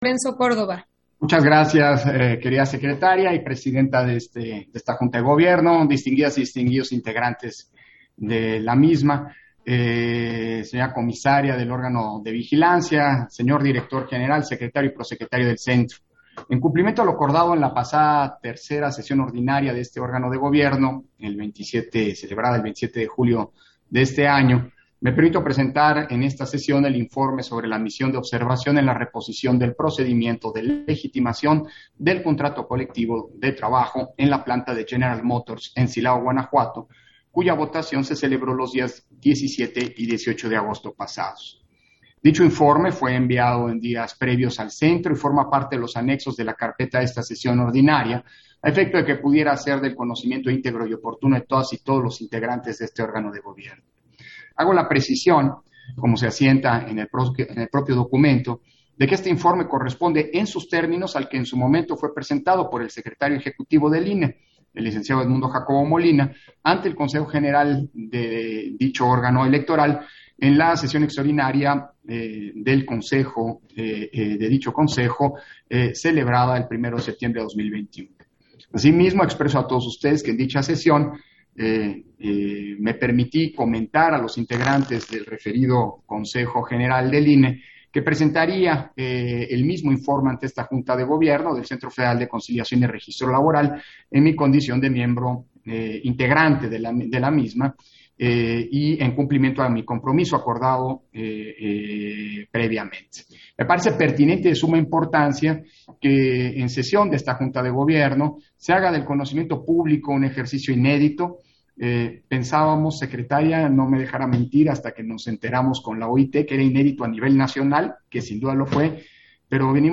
Intervención de Lorenzo Córdova, al presentar el informe sobre la misión de observación en la reposición del procedimiento de legitimación del contrato colectivo de trabajo en la planta de General Motors en Silao, Guanajuato